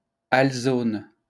Alzonne (French pronunciation: [alzɔn]